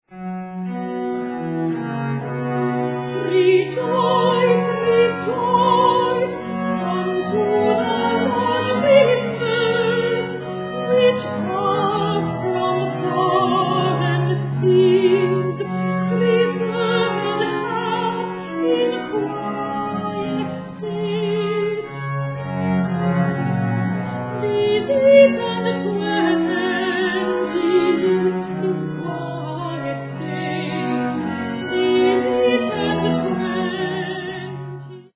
early music ensemble
soprano
harpsichordist
sacred song for voice & 4 viols